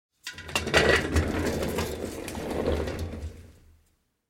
Звуки вагонетки
Откатили слегка назад